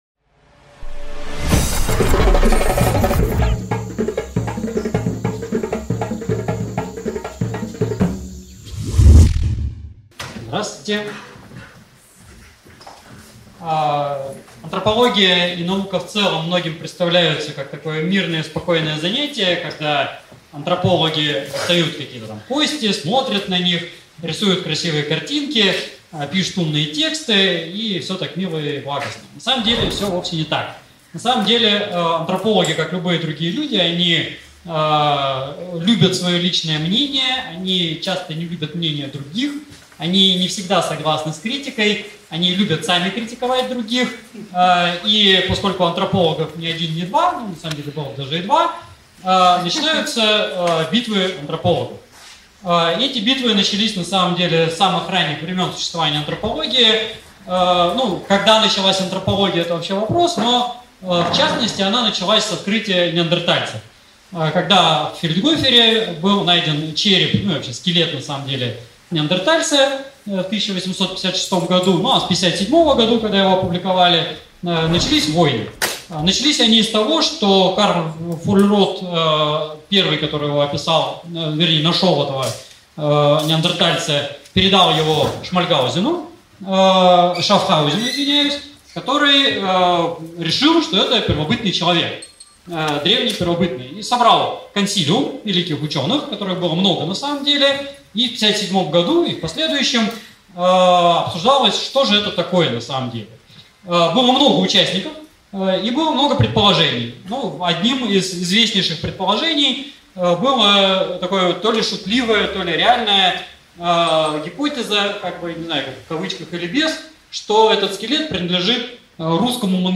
Аудиокнига Битвы Антропологов | Библиотека аудиокниг